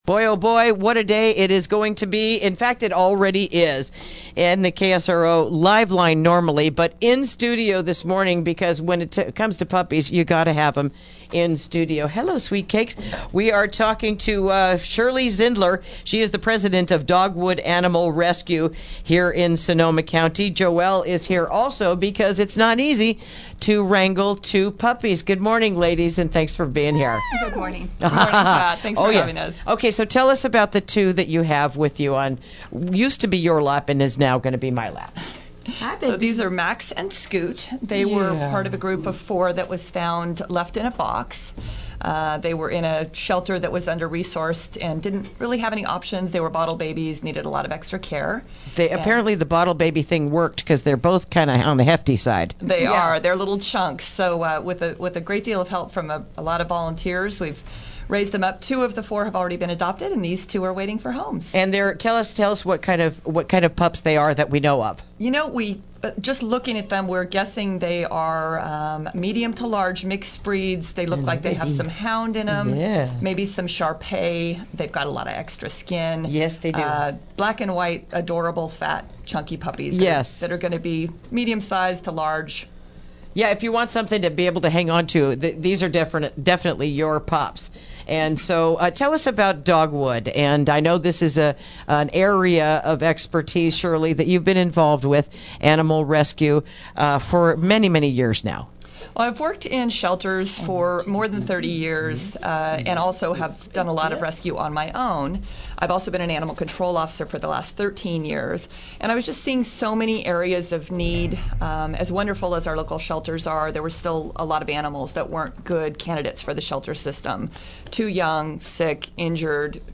Interview: Dogwood Animal Rescue